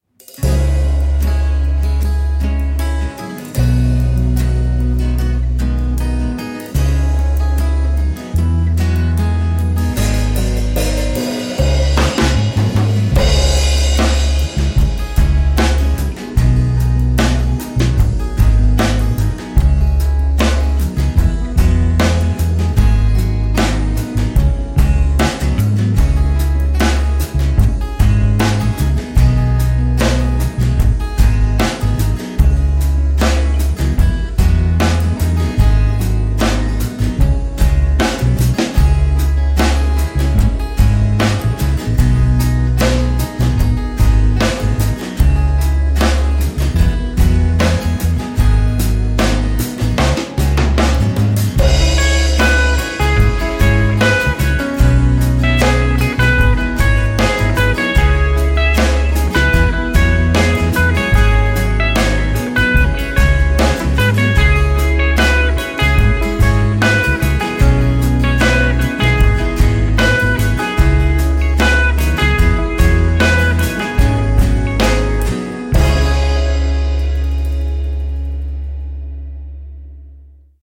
Zum Geburtstag viel Glück (Jazz)(MP3, 2 MB)
spielzeuglied-jan-2017-instrumental-master.mp3